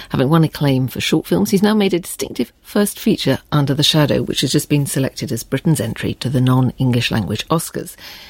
【英音模仿秀】《阴影之下》 听力文件下载—在线英语听力室